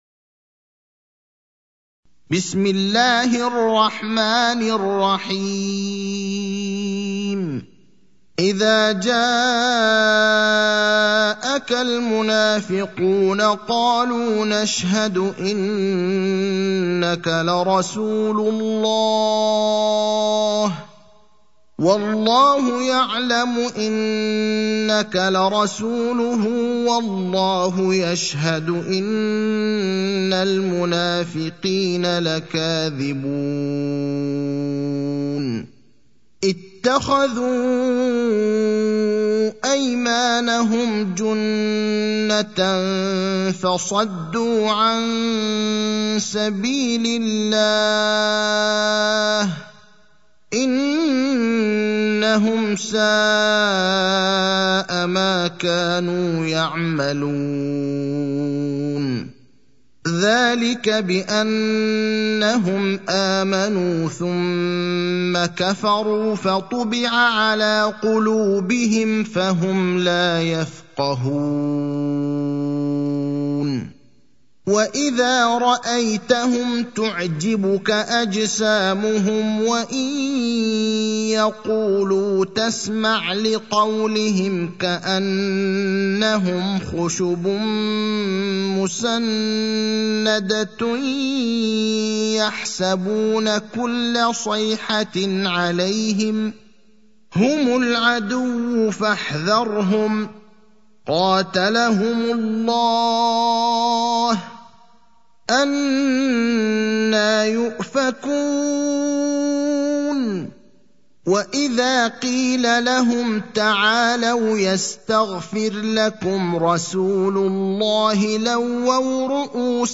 المكان: المسجد النبوي الشيخ: فضيلة الشيخ إبراهيم الأخضر فضيلة الشيخ إبراهيم الأخضر المنافقون (63)